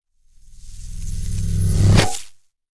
Звуки магнита
Звук притяжения мощного магнита (цепляют или отцепляют)